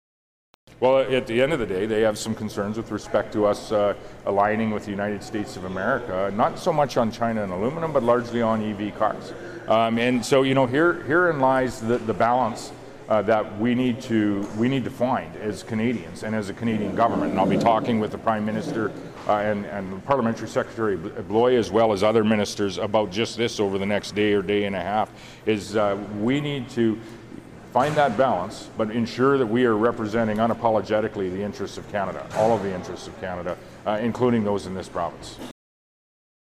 Moe spoke to reporters at the Saskatoon airport prior to departing on a flight to the nation’s capital for additional meetings with Prime Minister Mark Carney and senior cabinet ministers.